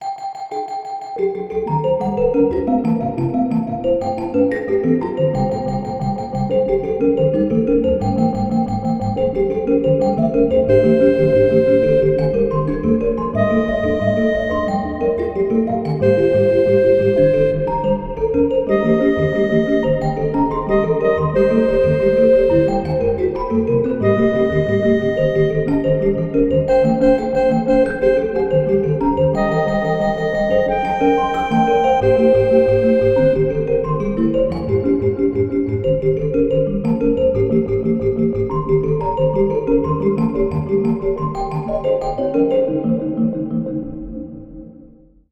Pieza de música atonal
Música electrónica
xilófono
percusión
melodía
repetitivo
rítmico
sintetizador